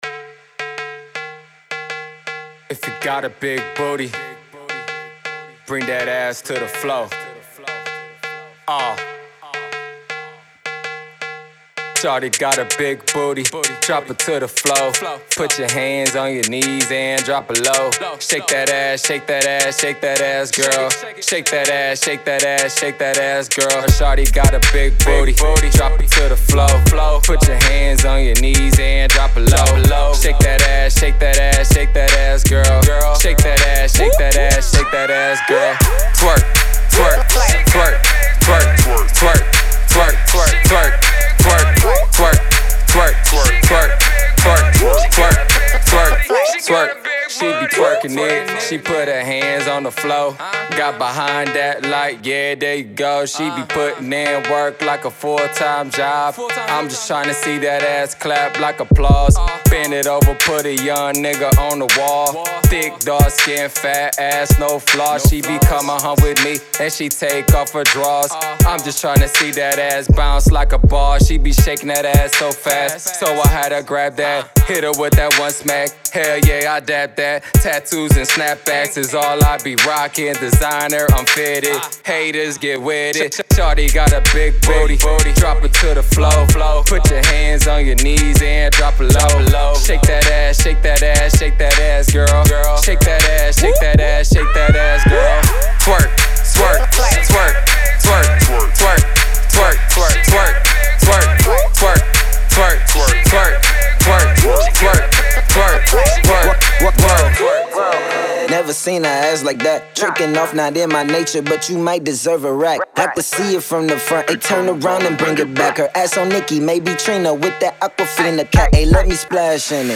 Summer Banger